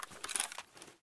draw_sling.ogg